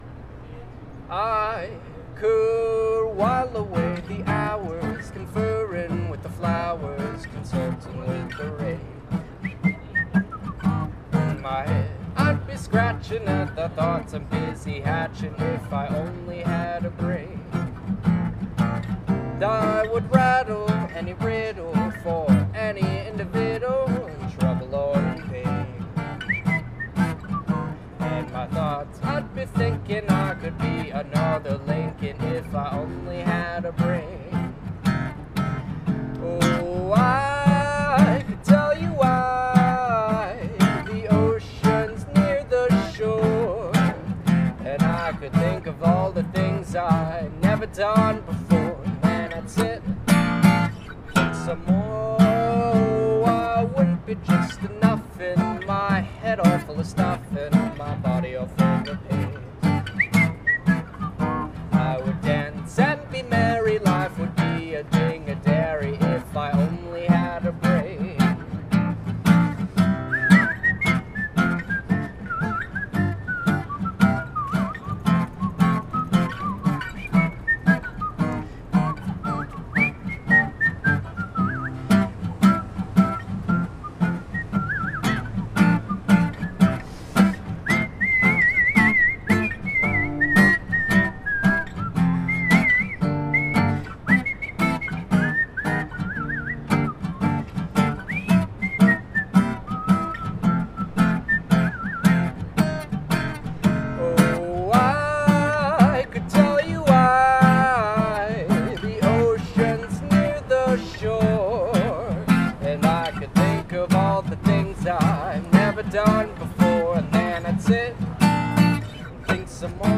performing in Asheville.